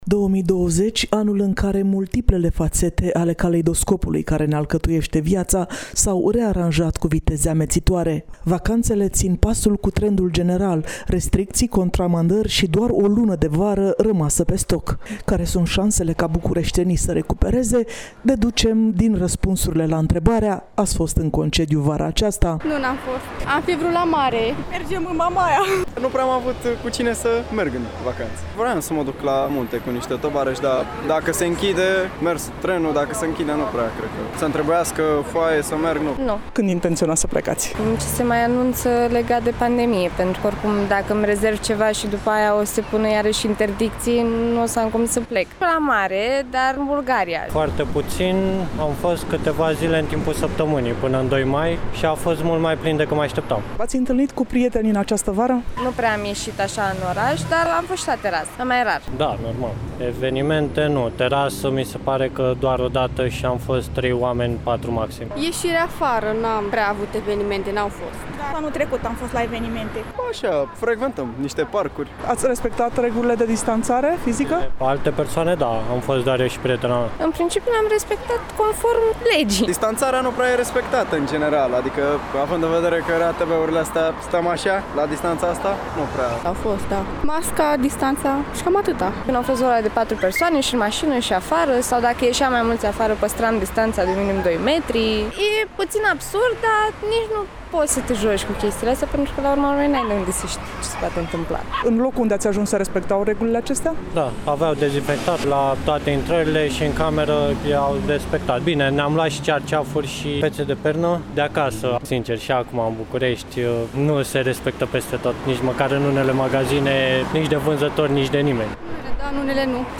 De asemenea, am întrebat bucureștenii dacă au fost în concediu, cu […]